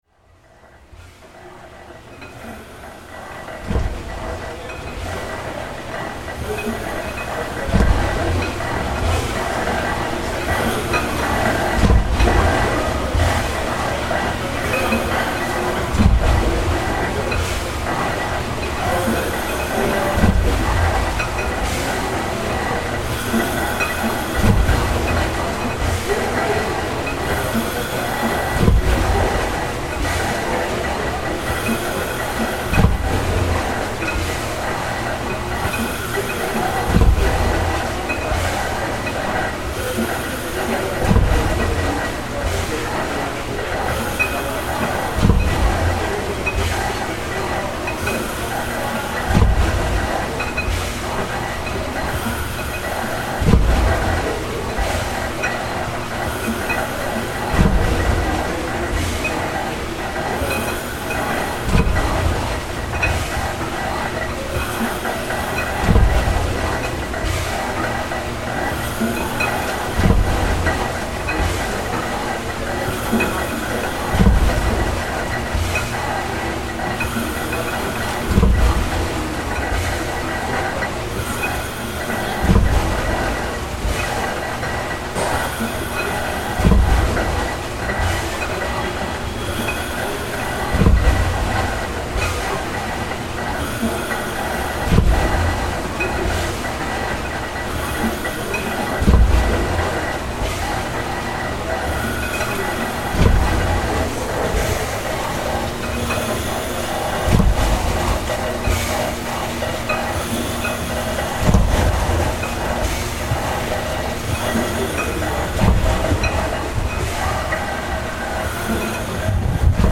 The sounds of a working steam-powered Victorian beam engine, recorded during one of its open-to-the-public steaming days. It’s located in the grounds of the former Tottenham Sewage Works, now a community park.